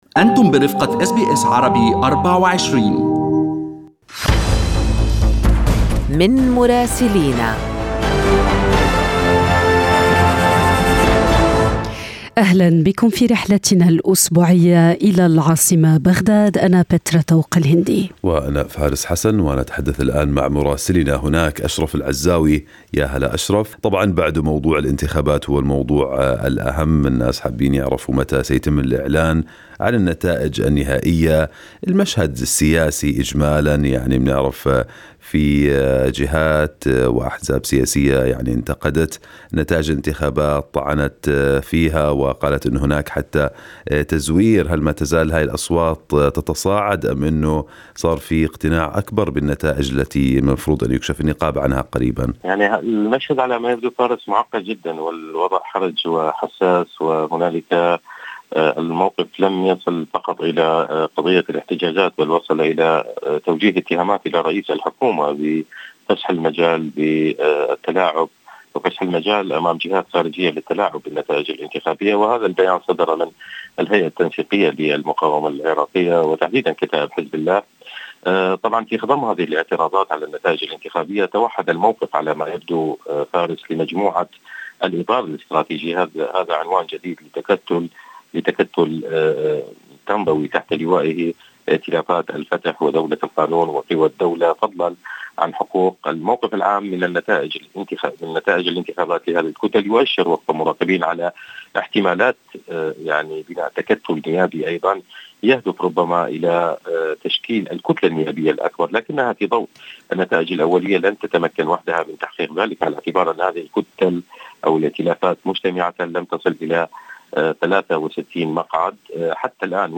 من مراسلينا: أخبار العراق في أسبوع 22/10/2021